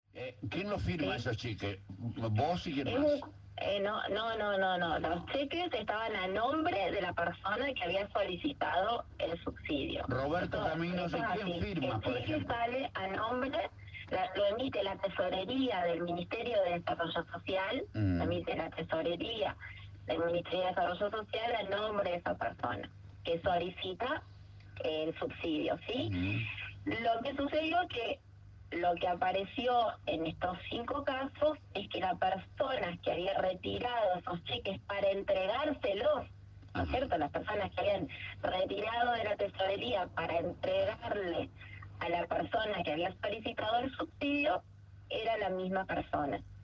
La vicegobernadora Laura Stratta eligió Radio LT 39 de Victoria para intentar minimizar el escándalo de los subsidios truchos que la tiene como protagonista y que ha generado un enorme escándalo en la ciudad de Victoria y en toda la Provincia.
Entrevista-a-Stratta-4.mp3